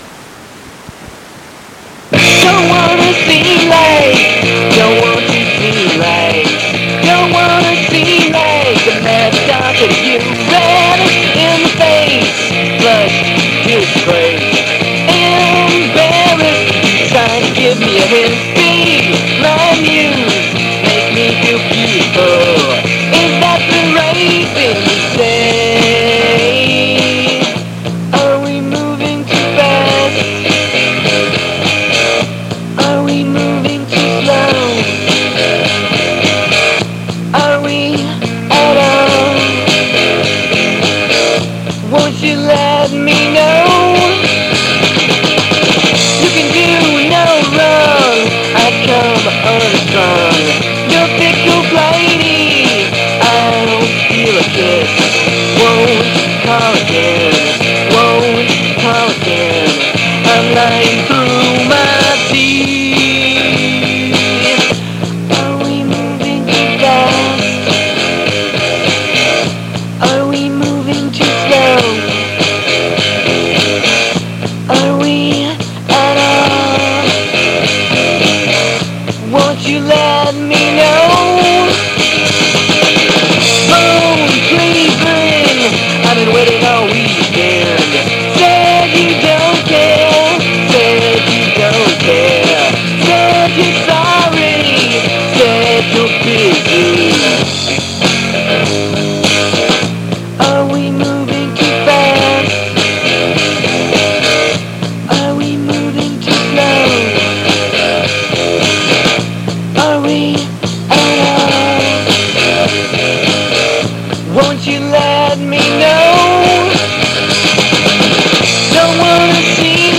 Guitar and Vocals
Bass
Session Drummer